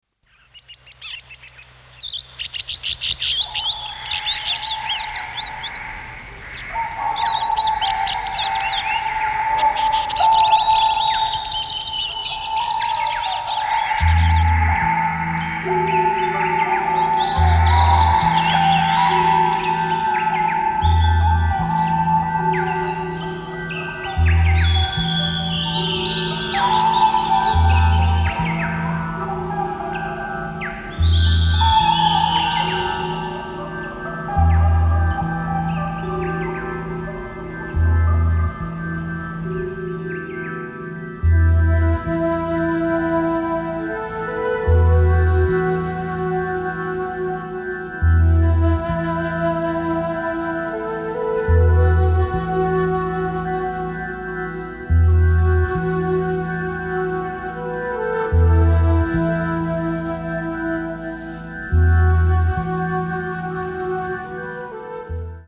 drums
trumpet/flugelhorn
elec. piano/Hammond Organ/piano
sop. & alt. & ten. sax/flute
elec.guitar/fork guitar/sitar